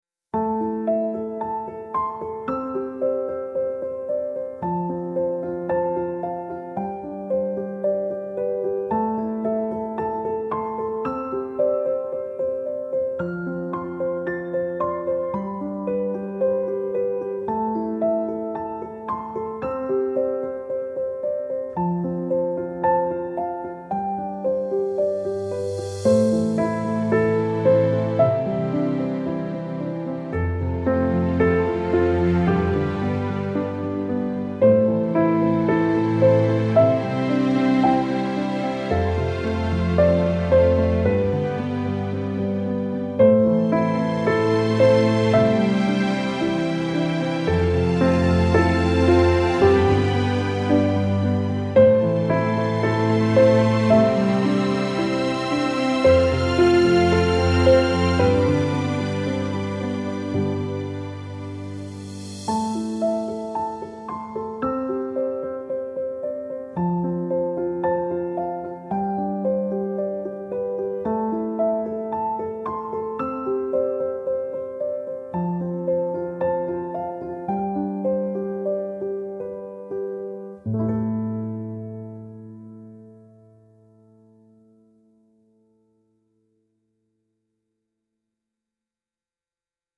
warm nostalgic piano melody with gentle strings, sentimental and heartfelt